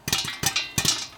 Pop4
ding field-recording metal-pan pop popcorn stove sound effect free sound royalty free Sound Effects